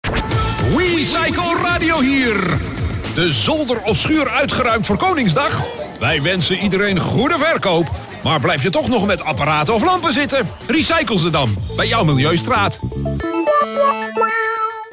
Radiocommercial